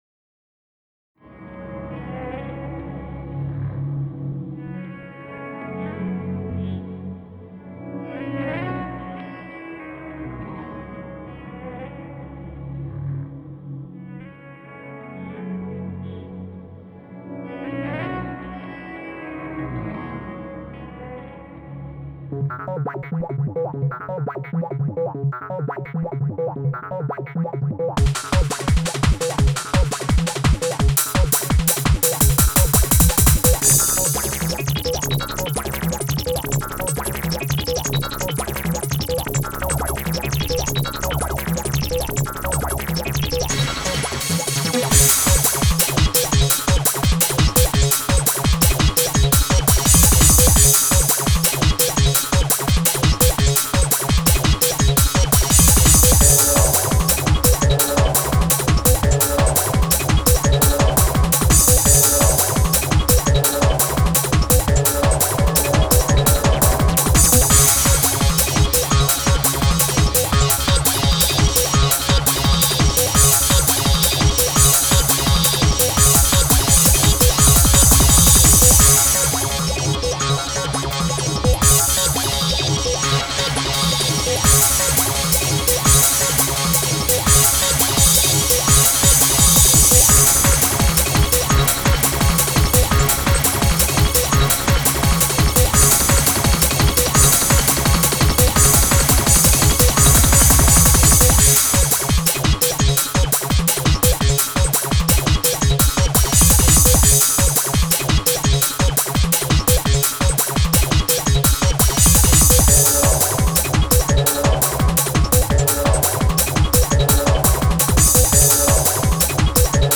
00's Trance Techno
ゲーム音楽としてBMSを作り始めた歴史が一番古い曲になるんだ。